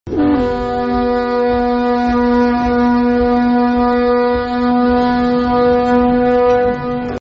War Horn Mordor